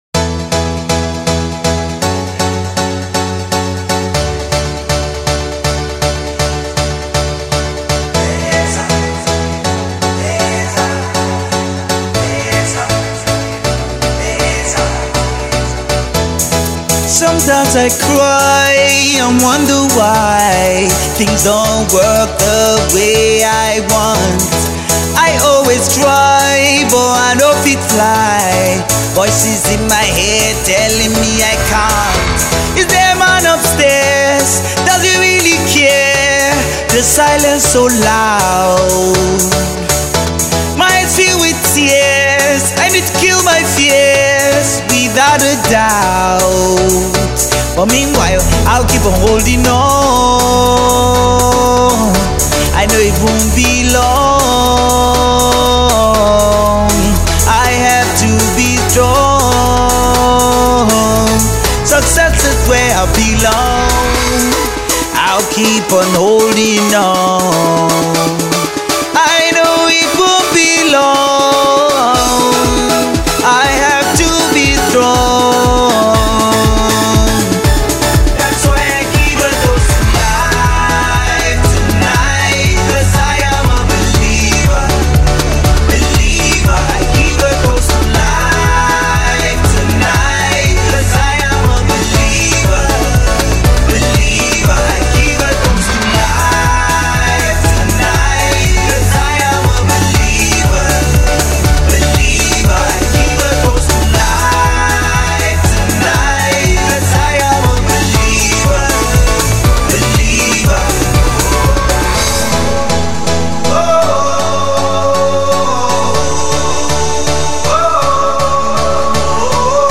Dance/Pop joint